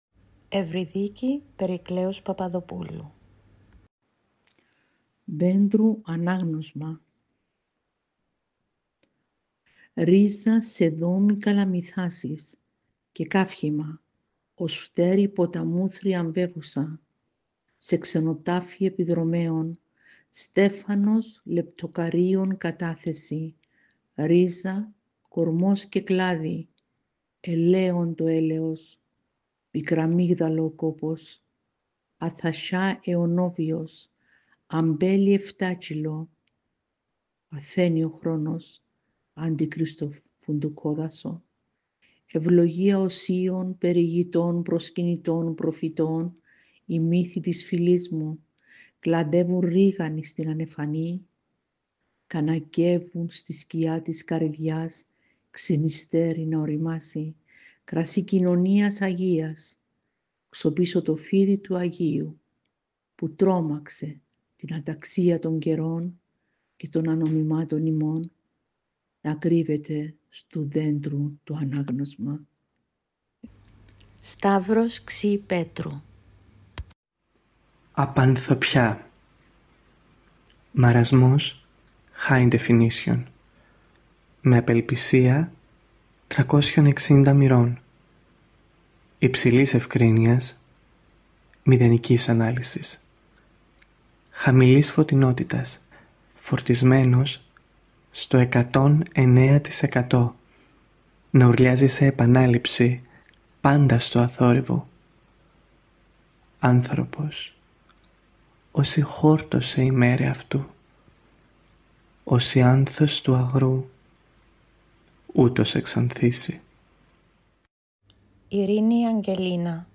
Στο MP3 θα ακούσετε τα ποιήματα ηχογραφημένα με τη φωνή των ίδιων των ποιητών